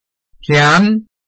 臺灣客語拼音學習網-客語聽讀拼-饒平腔-鼻尾韻
拼音查詢：【饒平腔】tiam ~請點選不同聲調拼音聽聽看!(例字漢字部分屬參考性質)